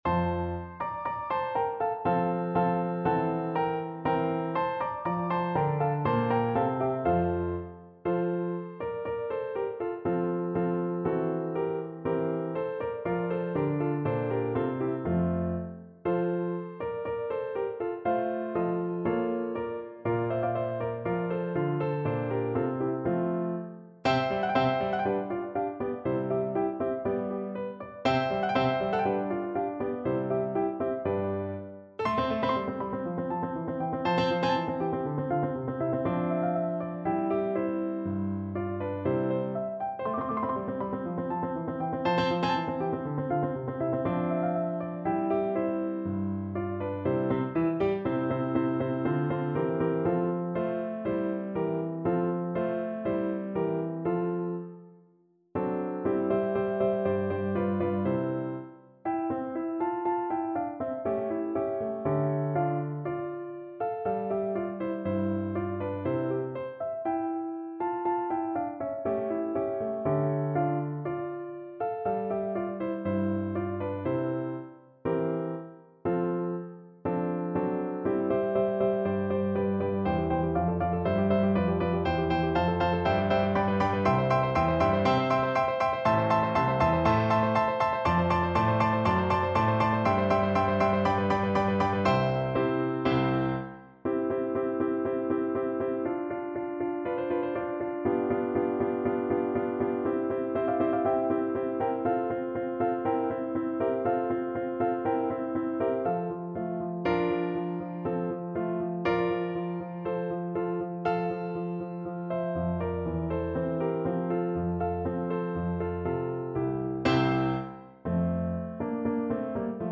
Free Sheet music for Soprano Voice
Play (or use space bar on your keyboard) Pause Music Playalong - Piano Accompaniment Playalong Band Accompaniment not yet available transpose reset tempo print settings full screen
Soprano Voice
F major (Sounding Pitch) (View more F major Music for Soprano Voice )
Allegro non troppo (View more music marked Allegro)
2/4 (View more 2/4 Music)
Classical (View more Classical Soprano Voice Music)